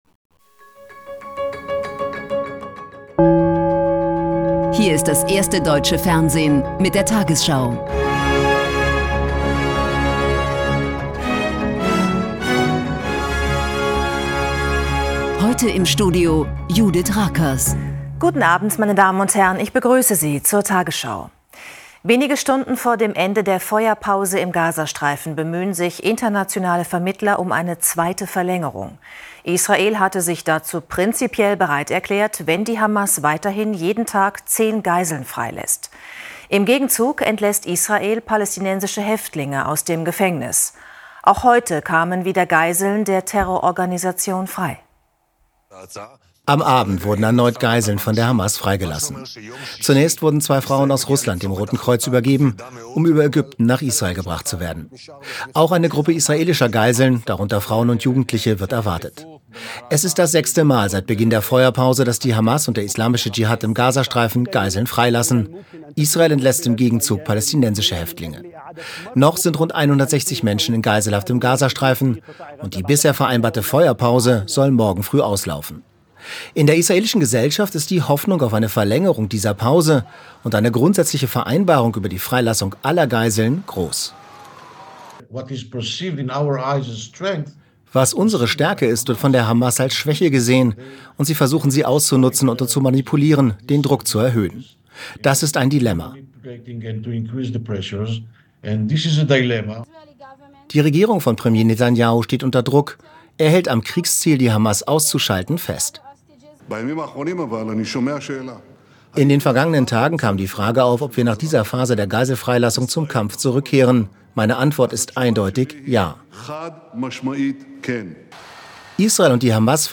Deutschlands erfolgreichste Nachrichtensendung als Audio-Podcast.